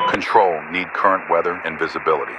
Radio-playerATCWeatherUpdate2.ogg